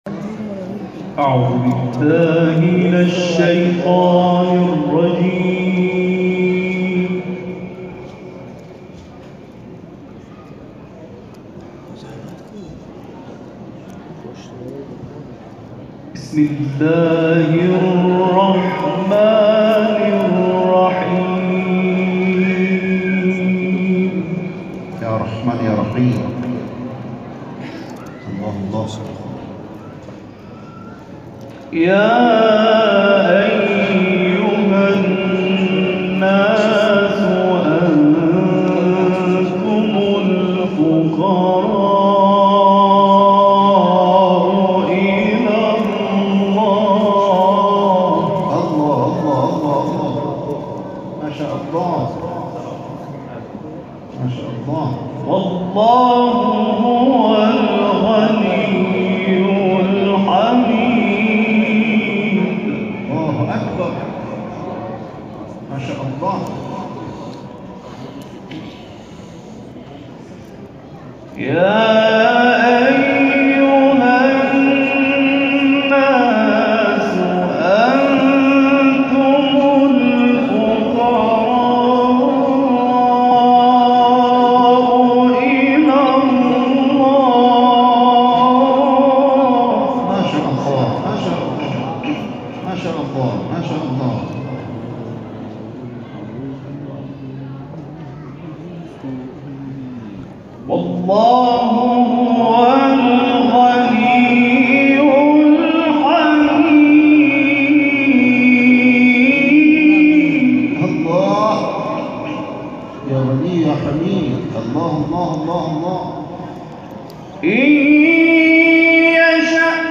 در دارالقرآن حرم مطهر امام رضا(ع)، در جوار مضجع شریف سلطان طوس، بعد از نماز مغرب و عشاء برگزار شد.
به تلاوت آیاتی از کلام الله مجید پرداختند که در ادامه ارائه می‌شود.